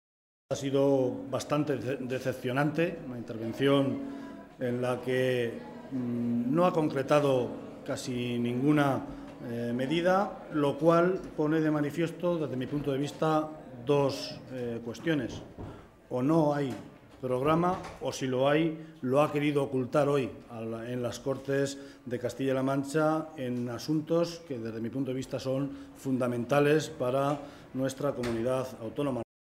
José Luis Martínez Guijarro, portavoz del Grupo Parlamentario Socialista en las Cortes de Castilla-La Mancha
Cortes de audio de la rueda de prensa